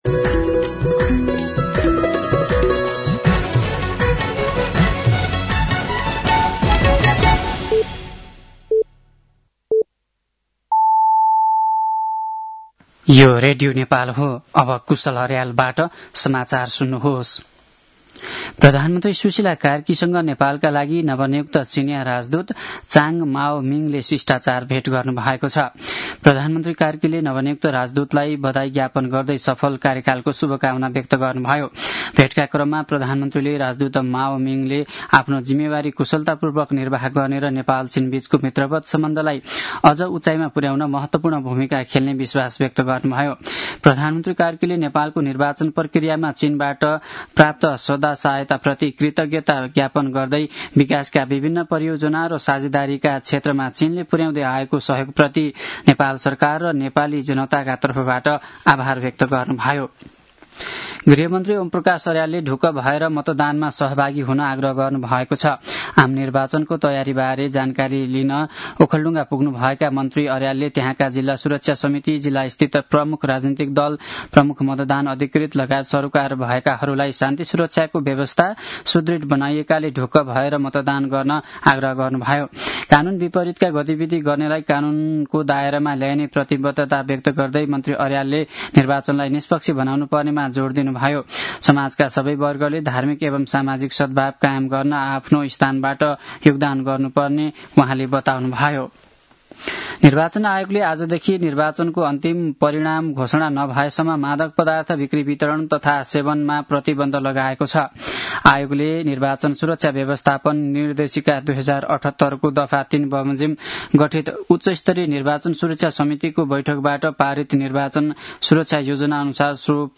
साँझ ५ बजेको नेपाली समाचार : १५ फागुन , २०८२